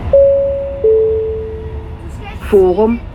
Højttalerudkald Metro og Letbane